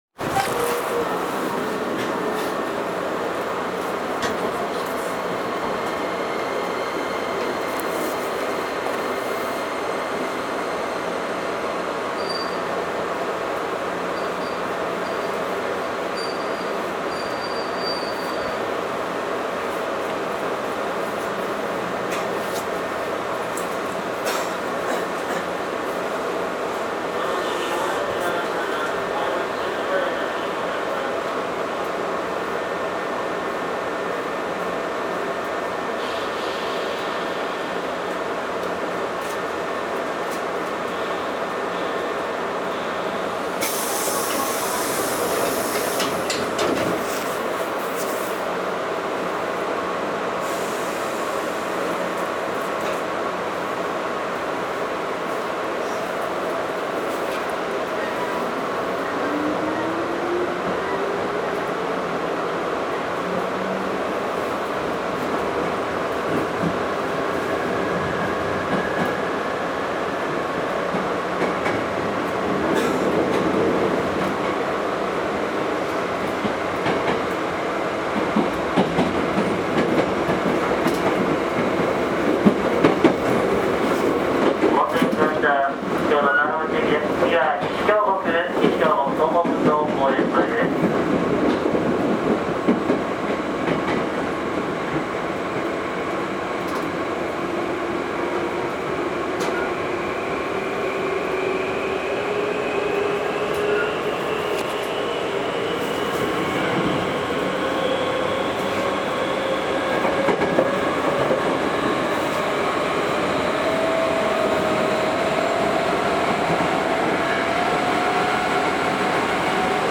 走行音
録音区間：桂～西京極(準急)(お持ち帰り)